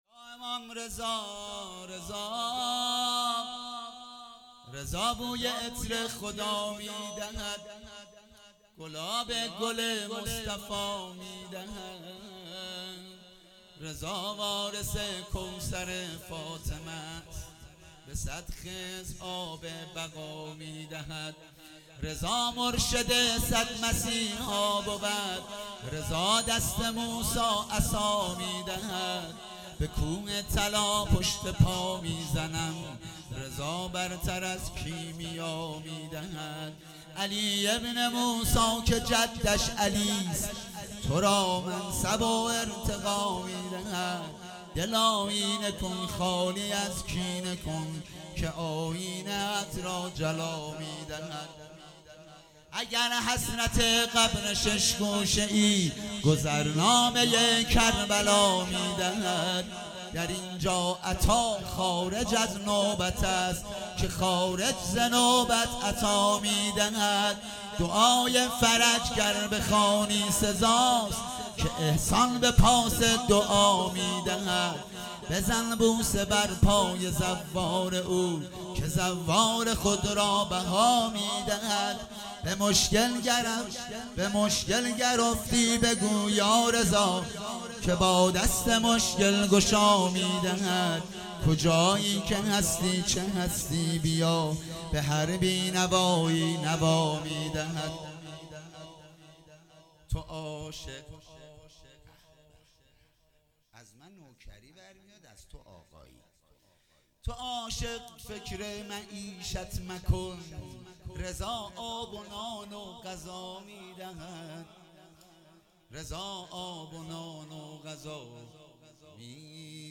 هفتگی 12 مرداد - مدح - رضا بوی عطر خدا میدهی